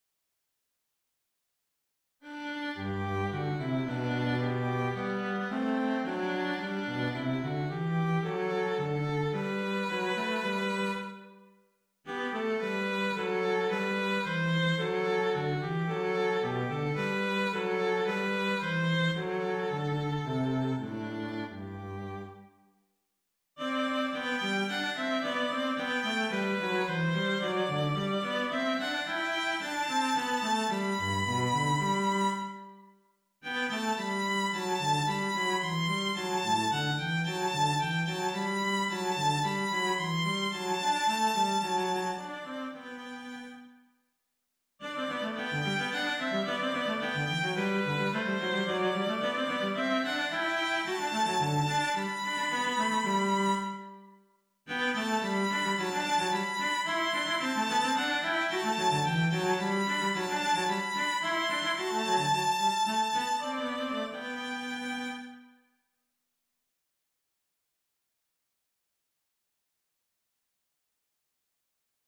(G)